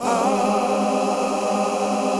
DM PAD4-09.wav